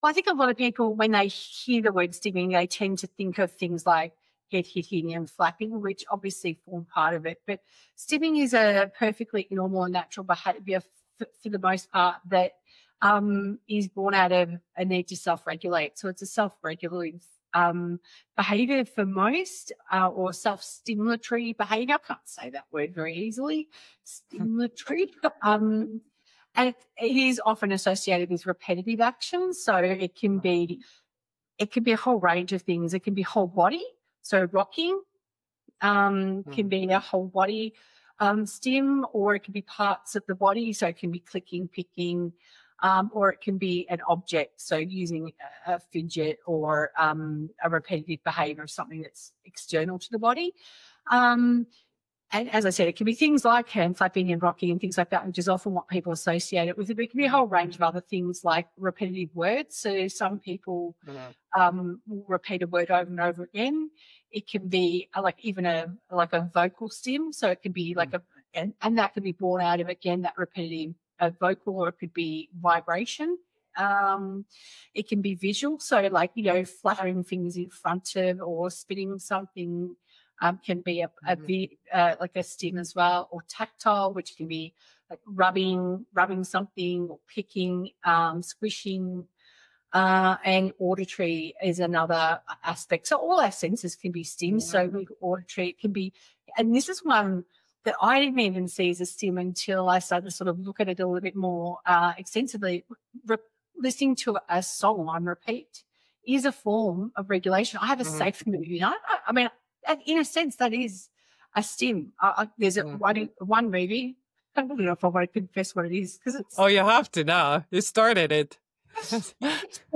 It was a fascinating conversation, and the replays are available now if you want to tune in.